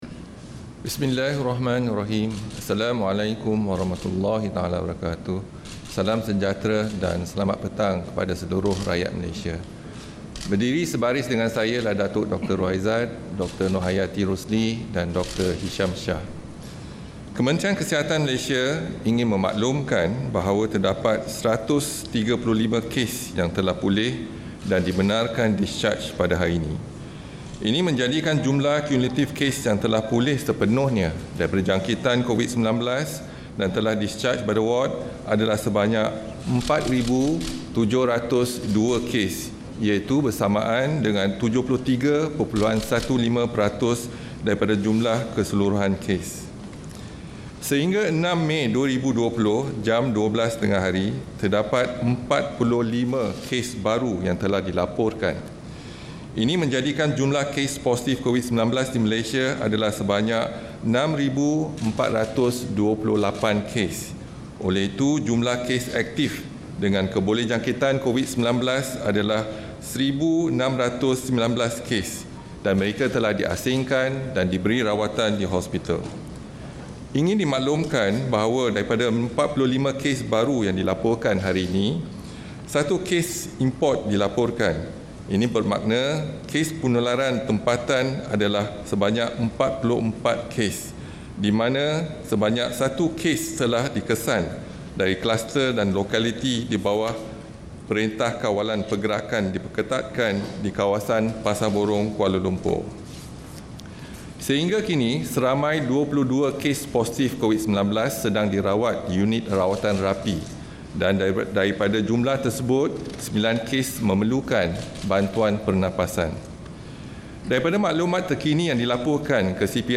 Ikuti sidang media oleh Ketua Pengarah Kementerian Kesihatan, Datuk Dr. Noor Hisham Abdullah berkaitan kemaskini penularan wabak COVID-19.